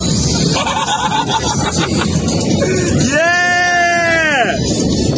The Laugh 1 Sound Button - Free Download & Play